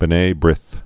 (bnā brĭth)